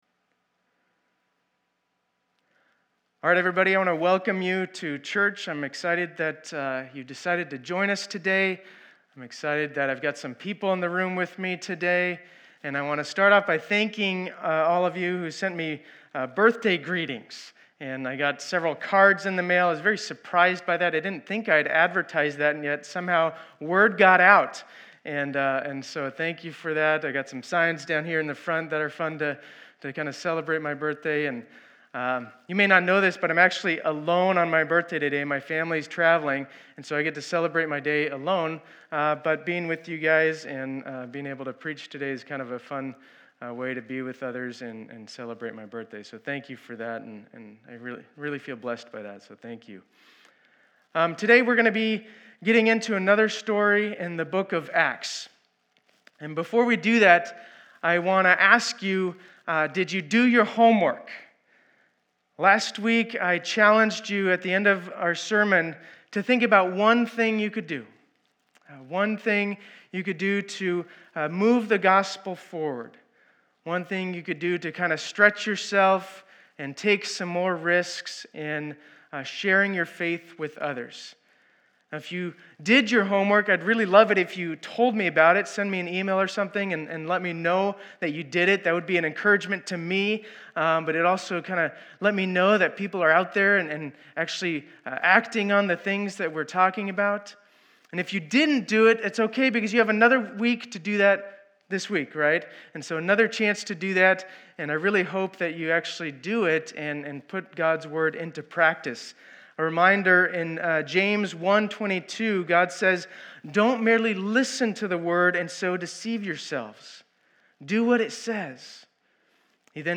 2020-07-05 Sunday Service
Intro, Teaching, Communion, and Lord’s Prayer